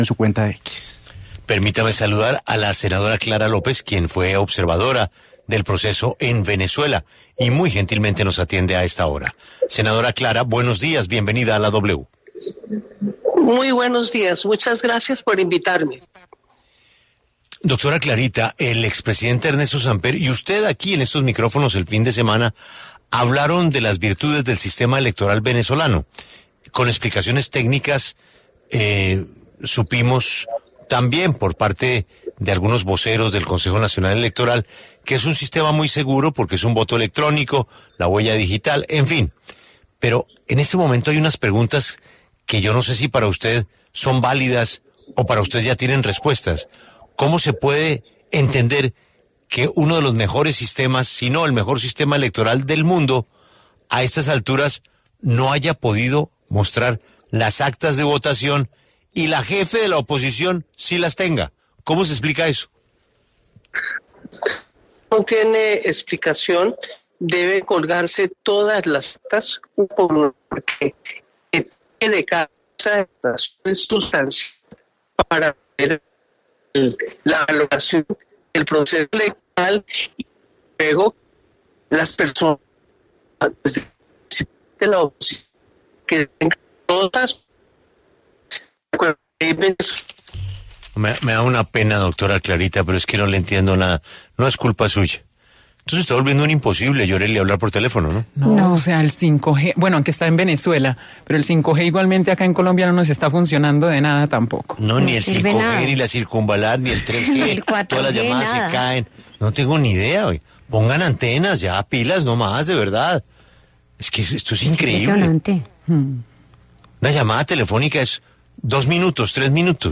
Clara López, senadora de Colombia y observadora electoral en Venezuela, habló en La W sobre la polémica de las actas en ese país.